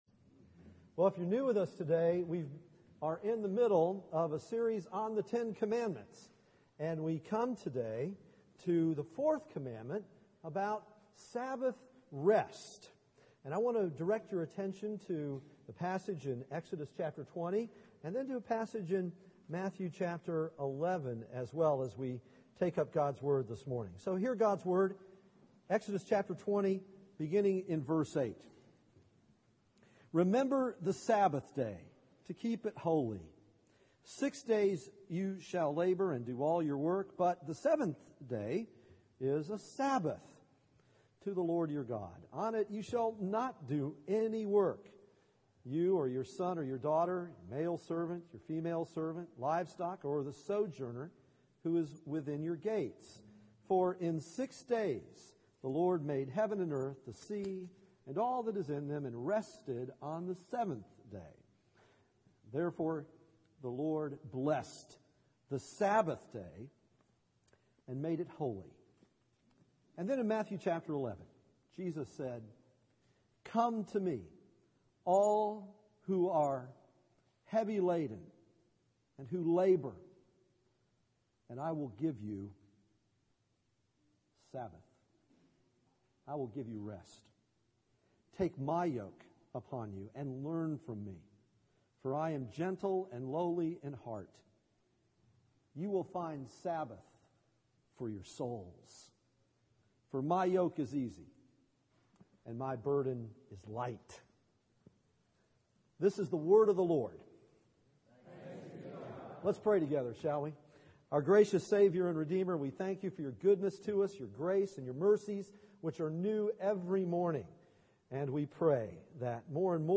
Matthew 11:28-30 Service Type: Sunday Morning « LOL!